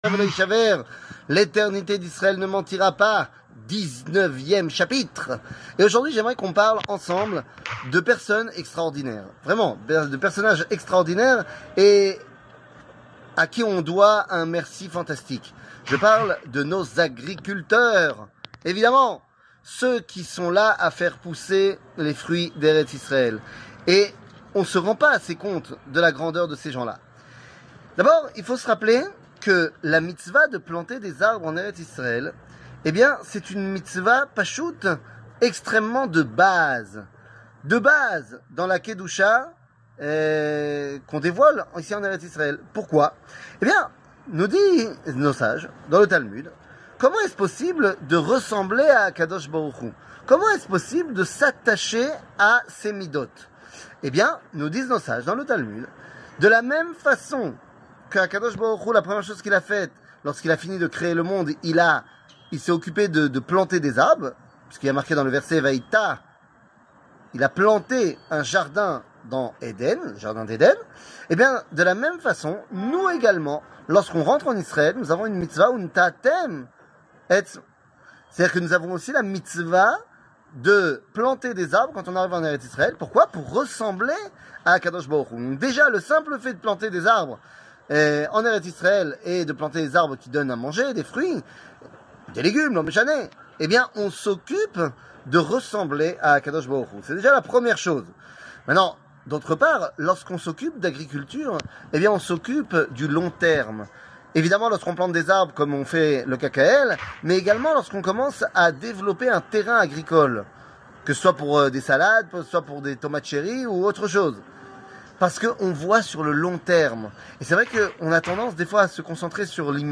L'éternité d'Israel ne mentira pas ! 19 00:05:01 L'éternité d'Israel ne mentira pas ! 19 שיעור מ 02 נובמבר 2023 05MIN הורדה בקובץ אודיו MP3 (4.58 Mo) הורדה בקובץ וידאו MP4 (7.41 Mo) TAGS : שיעורים קצרים